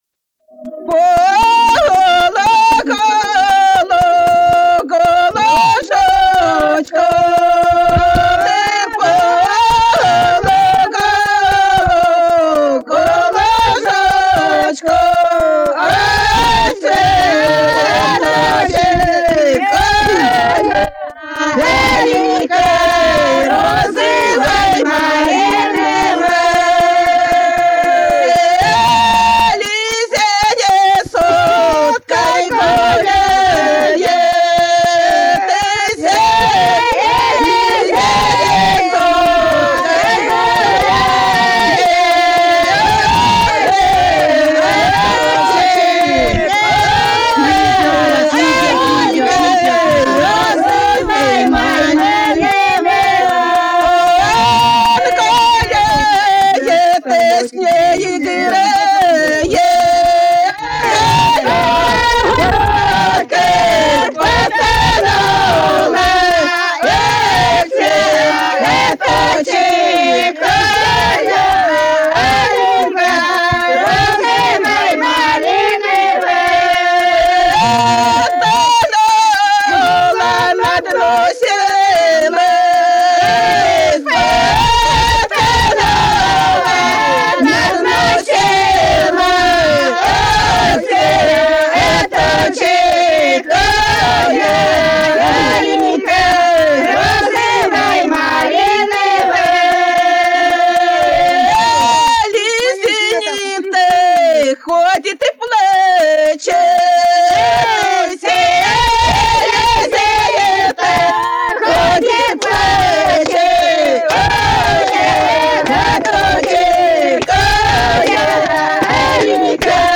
Народные песни Касимовского района Рязанской области «По лугу-лужочку», хороводная.